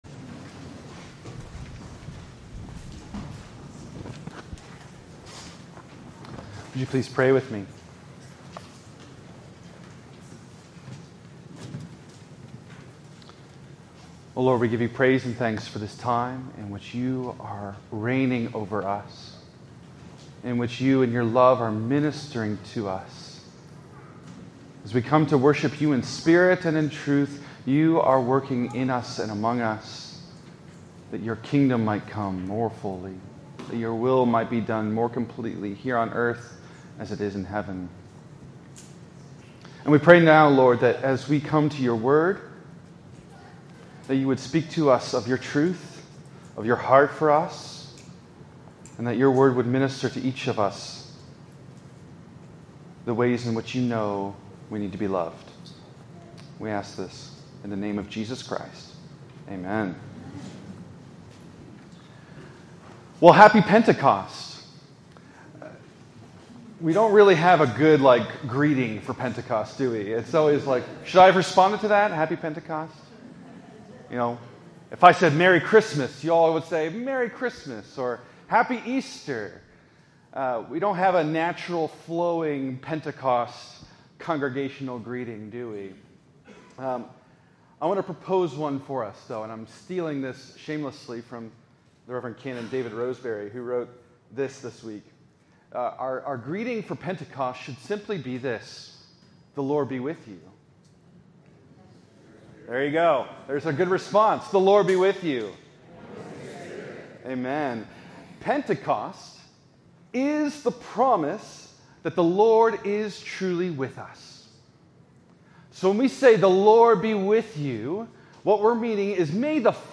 In this sermon from Pentecost 2025
Worship